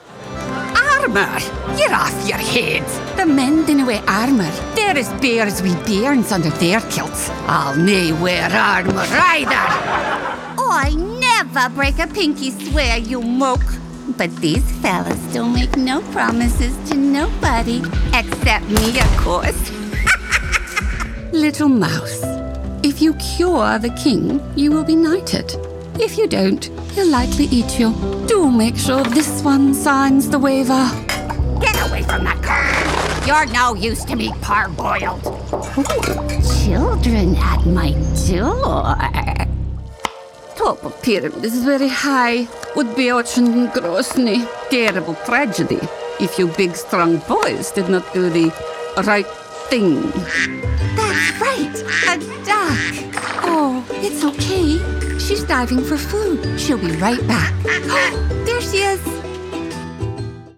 Female Voice Over Talent
Voice Over Talent
VOICE AGE RANGE – Commercial/Narration – 25 - 100, Animation/Video Games – 25 - Ancient
STUDIO – Professional quality, acoustically treated home studio, Rode NT1A mic, Focusrite Scarlett 2i2 audio interface, Reaper DAW, PC with 32GB RAM, running Windows 11